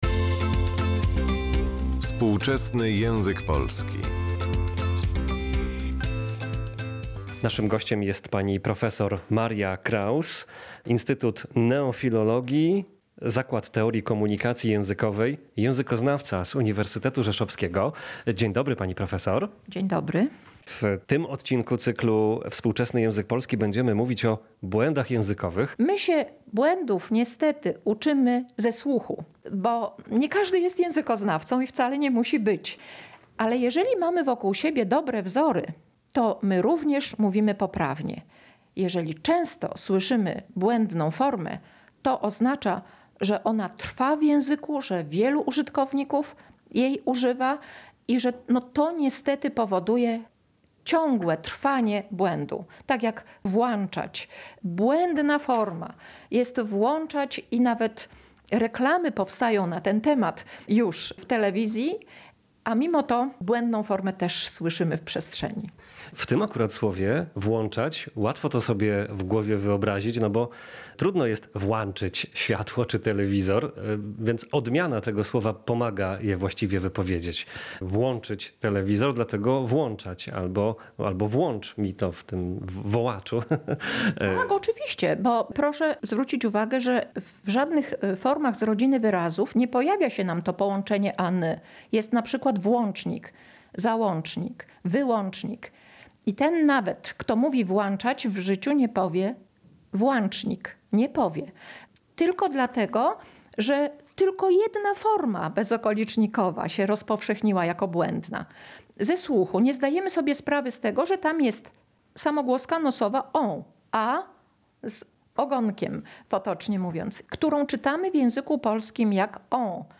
O najczęstszych błędach językowych i o tym, jak skutecznie się ich pozbyć, rozmawiamy w tym odcinku cyklu „Współczesny język polski”.